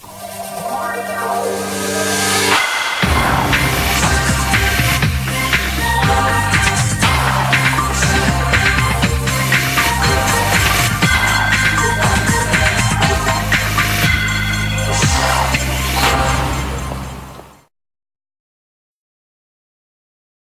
Générique